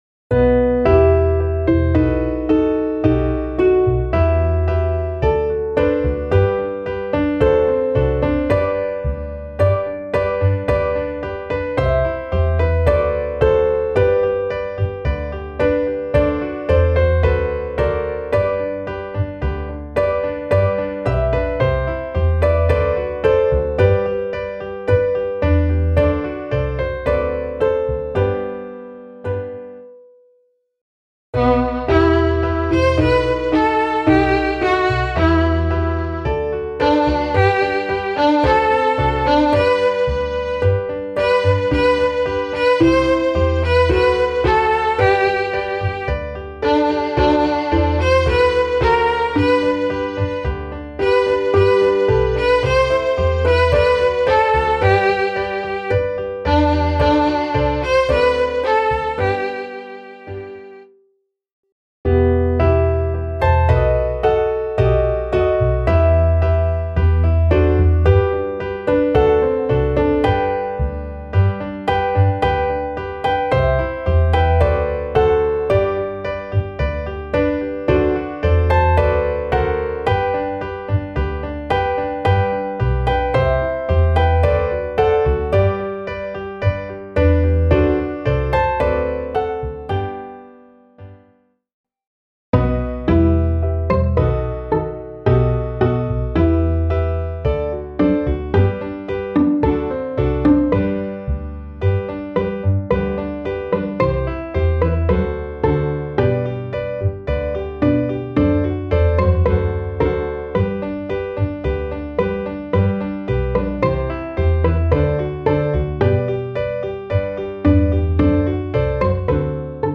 Klaviersatz
midi_der-kanonier_klavier_320.mp3